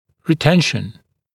[rɪ’tenʃn][ри’тэншн]ретенция, удерживание, сохранение